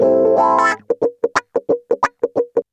Index of /90_sSampleCDs/USB Soundscan vol.04 - Electric & Acoustic Guitar Loops [AKAI] 1CD/Partition C/05-089GROWAH